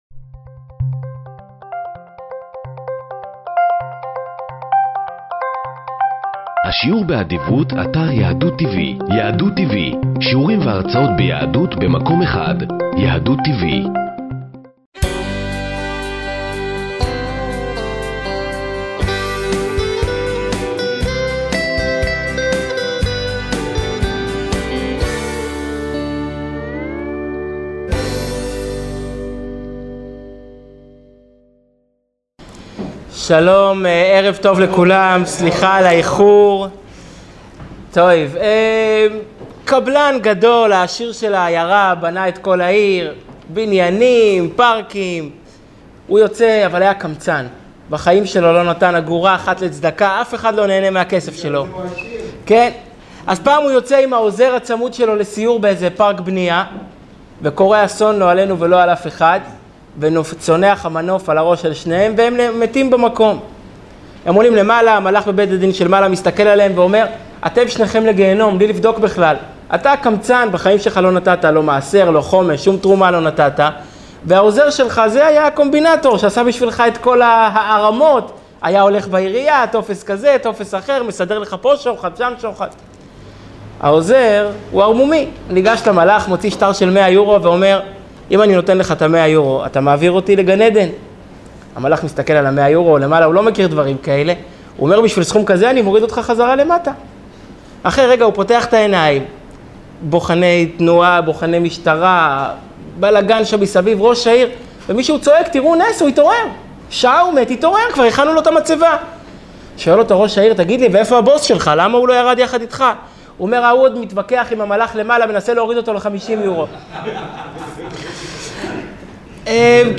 שנמסר בביהכנ"ס חב"ד בראשל"צ